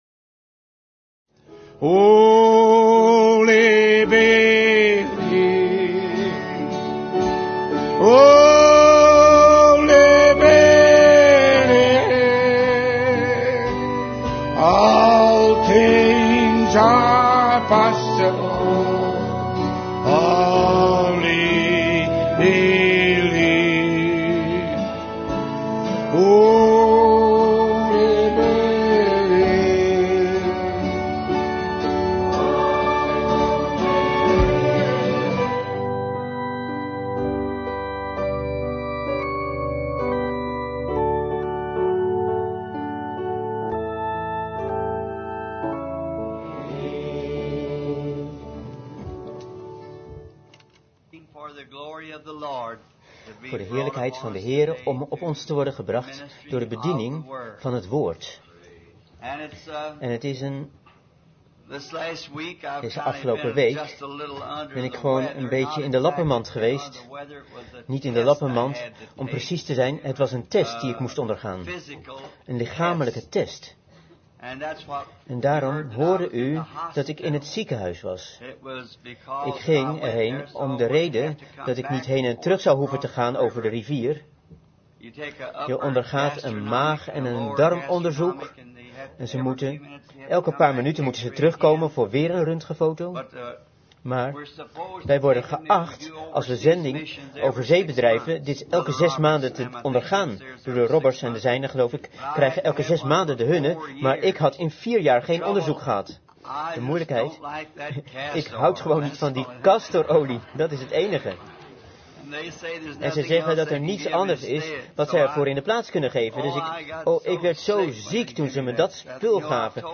Vertaalde prediking "Rejected King" door William Marrion Branham te Branham Tabernacle, Jeffersonville, Indiana, USA, 's ochtends op zondag 15 mei 1960